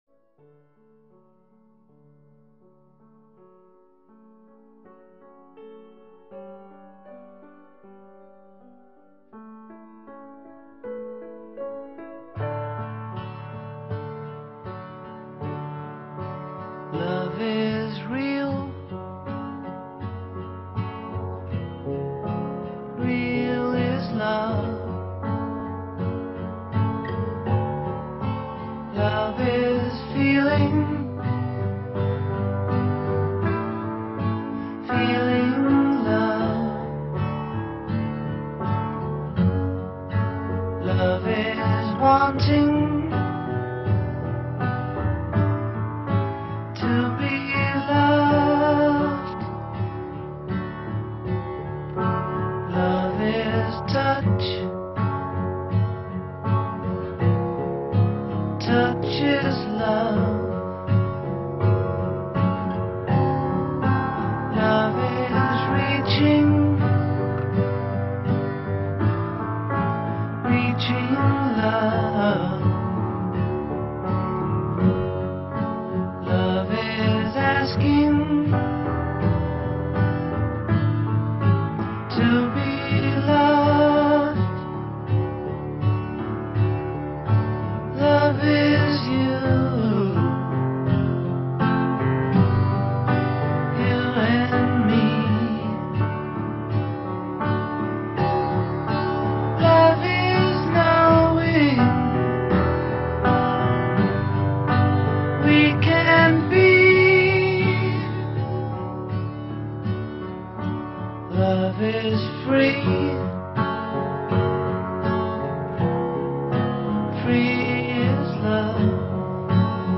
ترانه‌ی بسیار ساده و زیبای